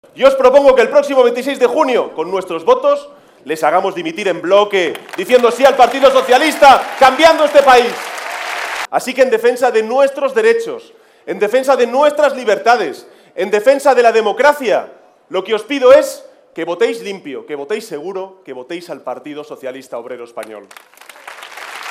En el mitín que ha tenido lugar en Albacete
Cortes de audio de la rueda de prensa